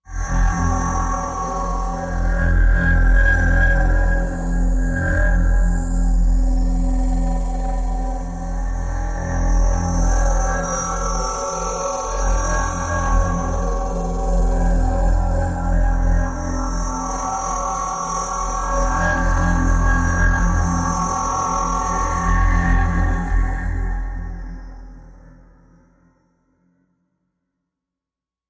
Nerve Deflector, Machine, Med. Pulse, High Electric Spin, Movement